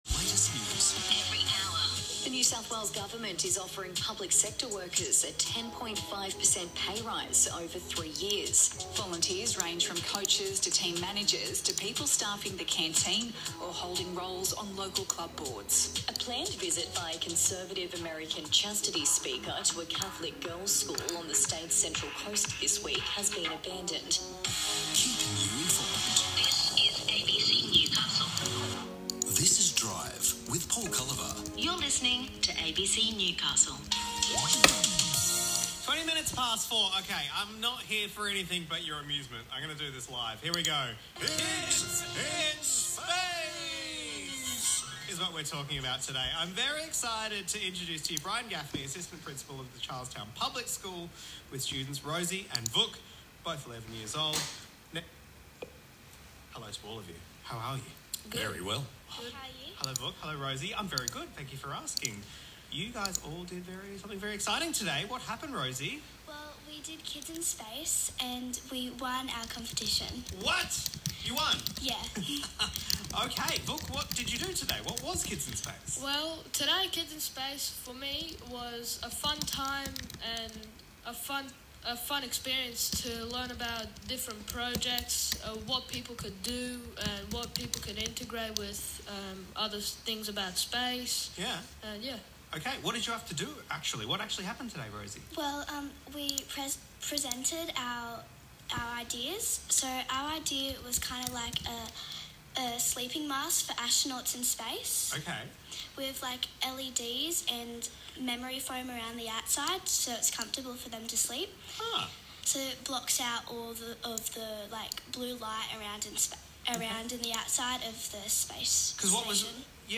abc-radio-newcastle-winners-interview.m4a